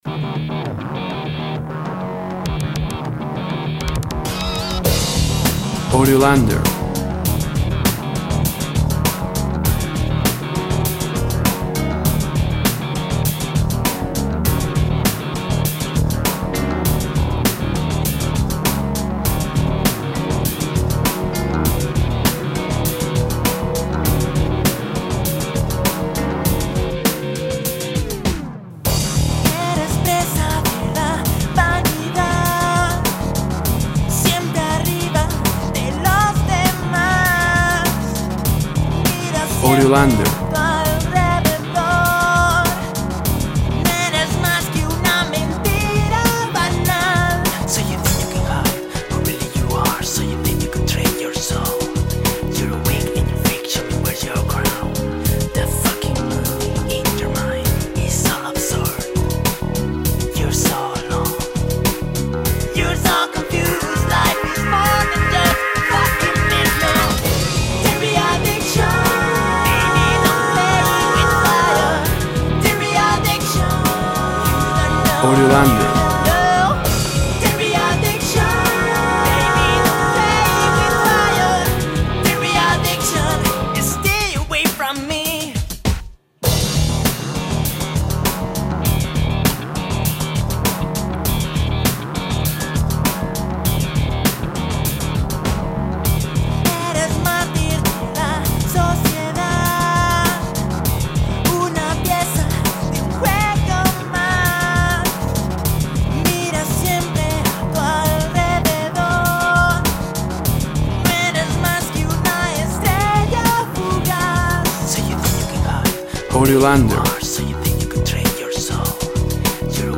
Dangerously, risky, dark.
Tempo (BPM) 100